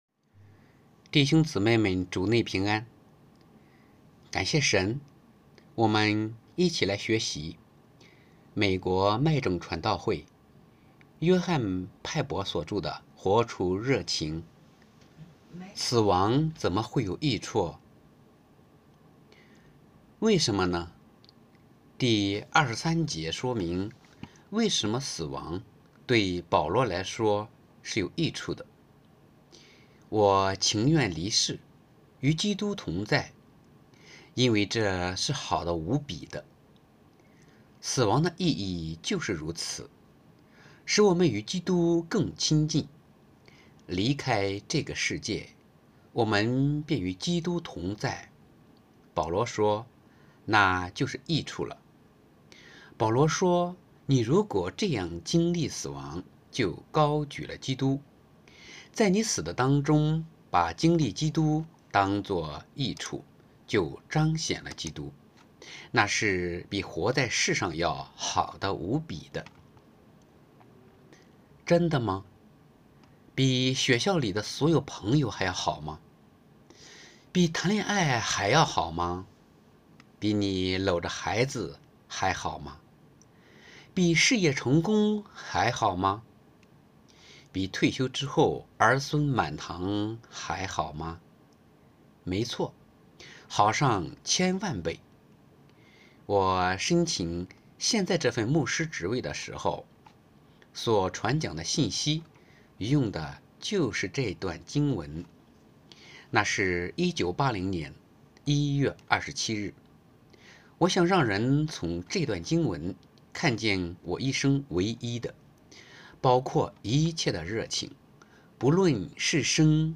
2023年11月30日 “伴你读书”，正在为您朗读：《活出热情》 音频 https